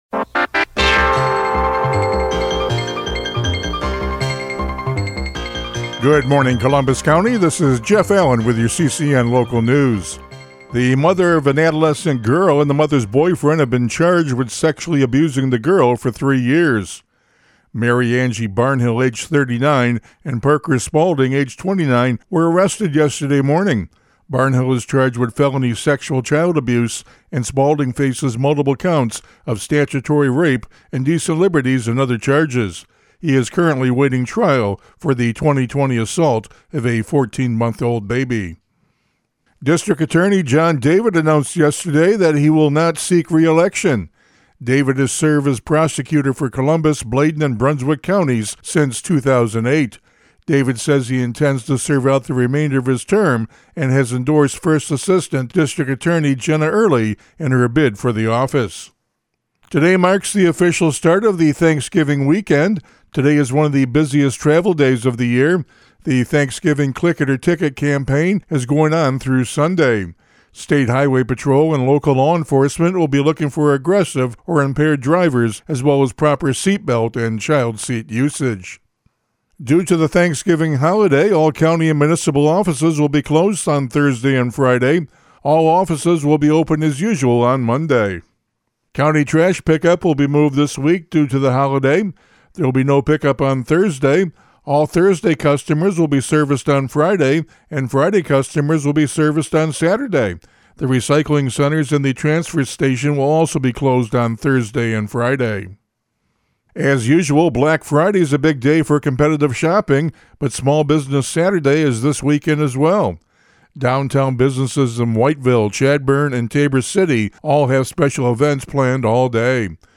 CCN Radio News — Morning Report for November 26, 2025